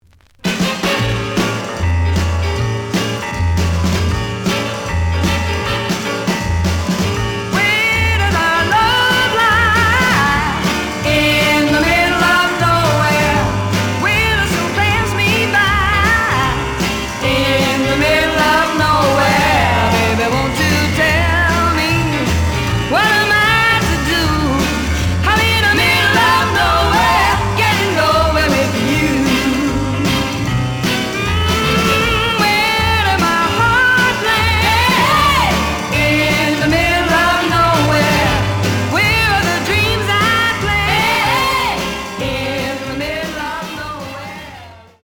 The audio sample is recorded from the actual item.
●Genre: Rock / Pop
A side plays good.